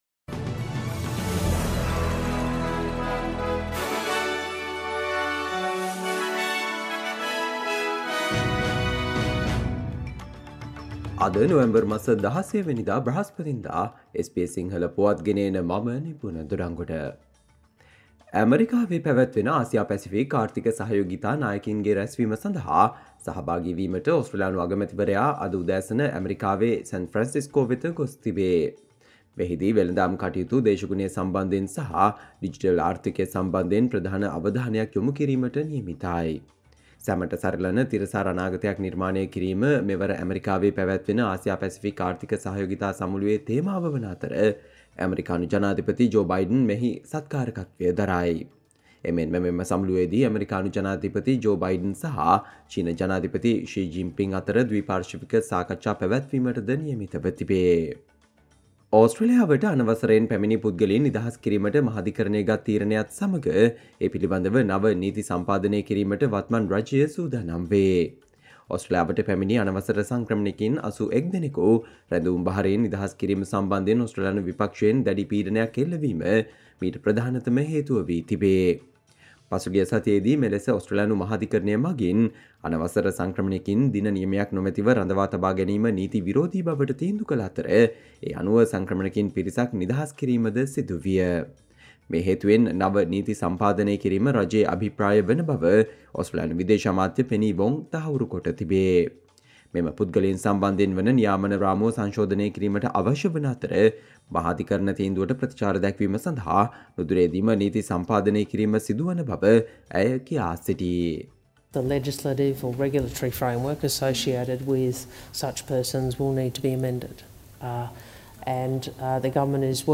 Australia news in Sinhala, foreign and sports news in brief - listen, Thursday 16 November 2023 SBS Sinhala Radio News Flash